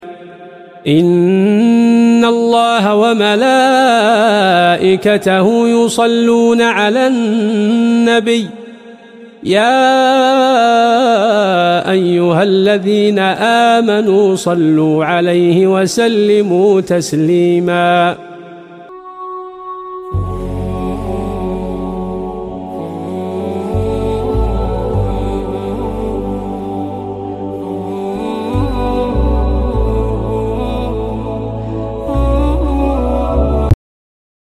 📖✨ Lecture du Saint Coran sound effects free download